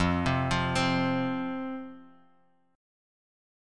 F5 Chord
Listen to F5 strummed